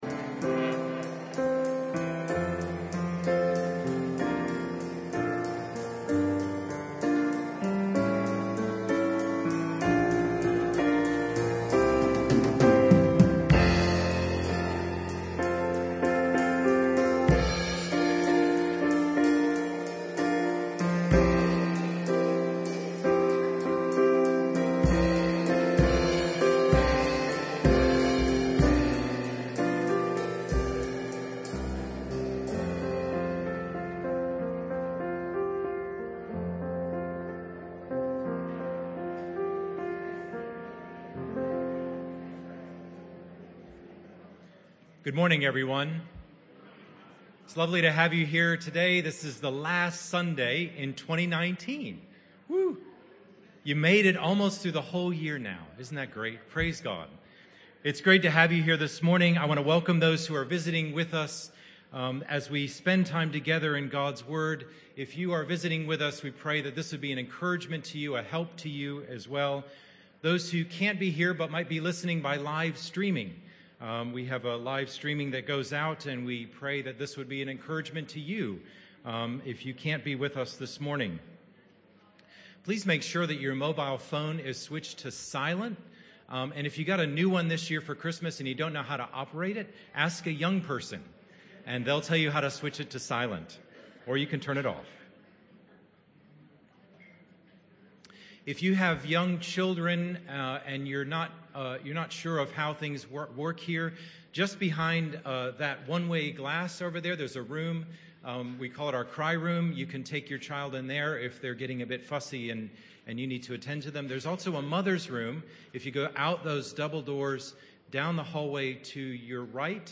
This talk that took place in the PM Service was part of the Proverbs series (Talk 5 of 8).